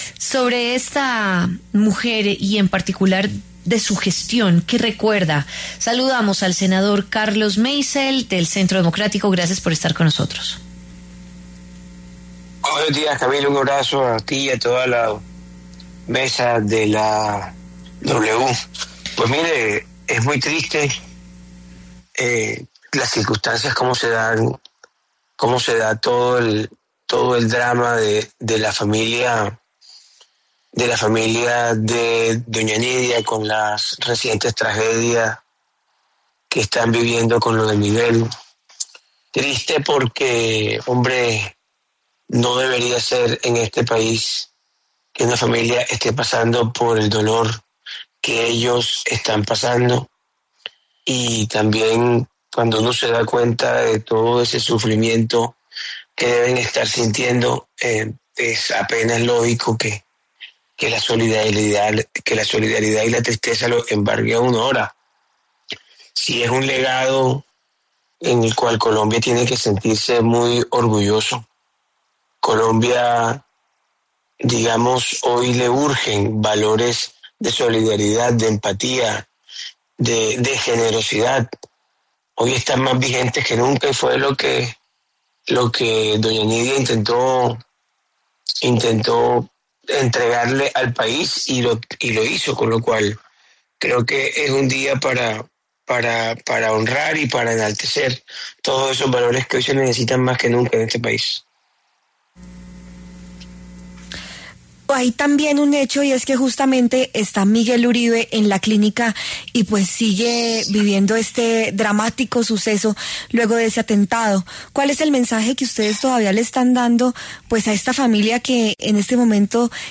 El senador Carlos Meisel, del Centro Democrático, habló con La W sobre el supuesto plan de Álvaro Leyva contra el presidente Gustavo Petro.